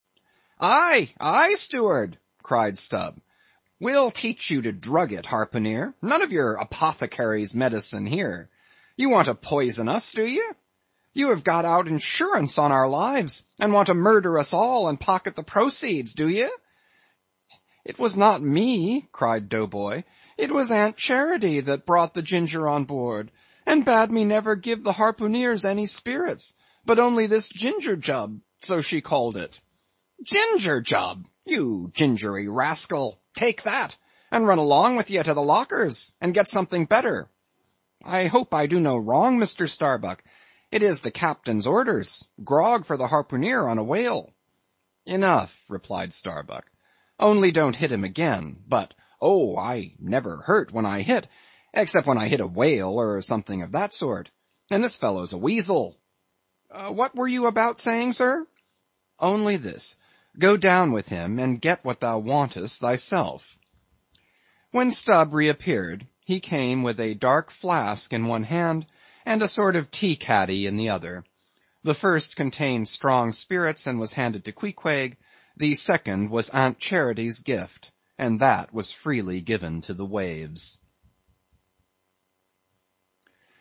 英语听书《白鲸记》第659期 听力文件下载—在线英语听力室